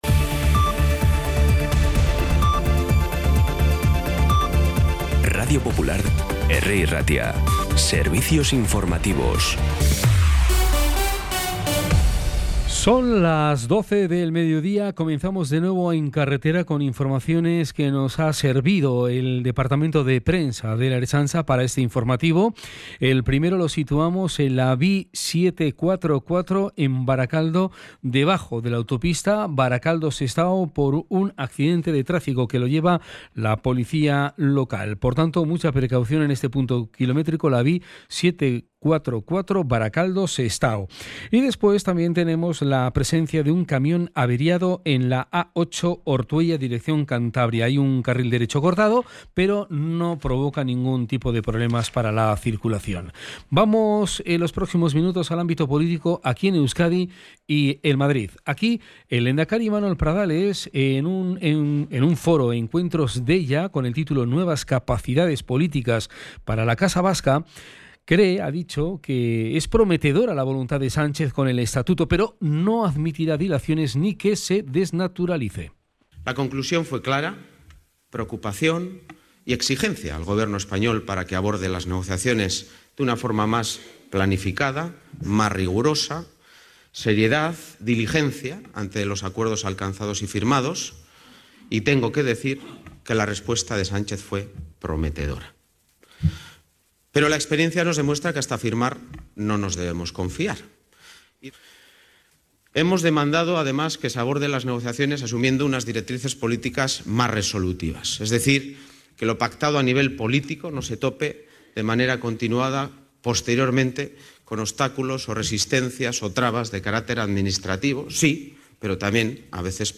Las noticias de Bilbao y Bizkaia del 11 de febrero a las 12
Los titulares actualizados con las voces del día.